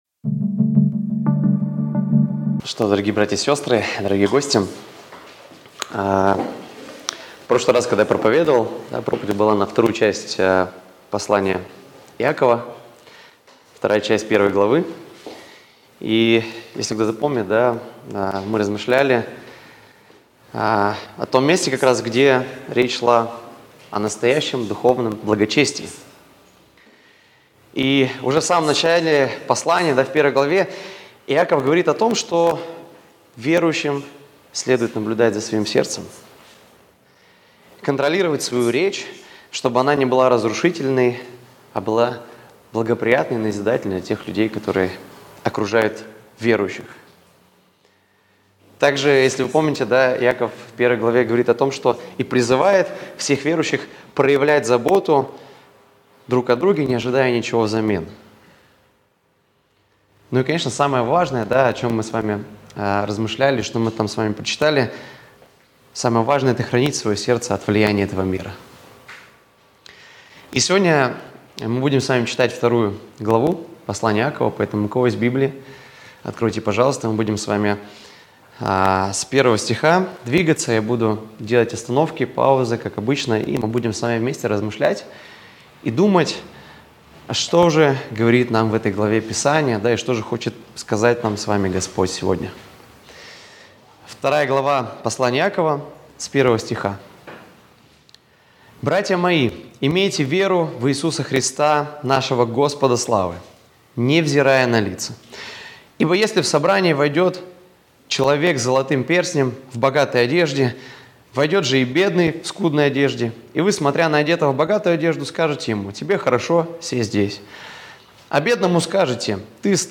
Воскресная проповедь - 2025-01-26 - Сайт церкви Преображение